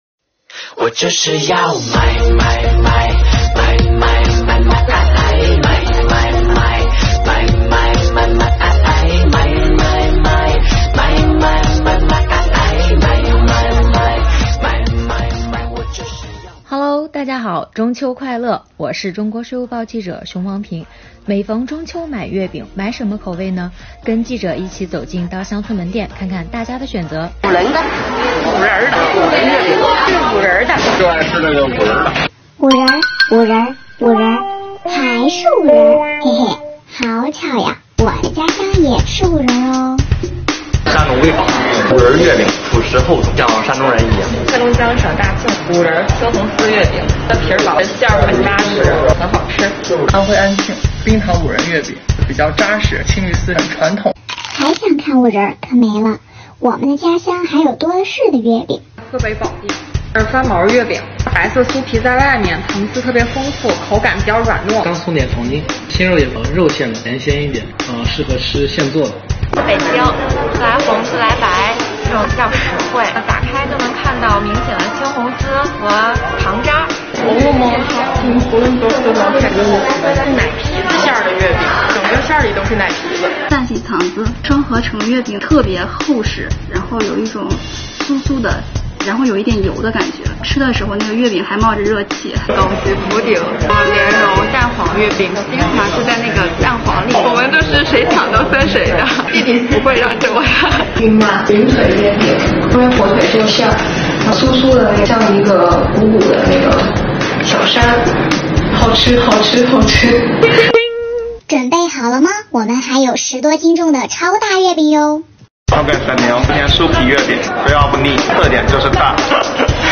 北京市东城区和通州区的税务小伙伴们分享了各自的月饼故事。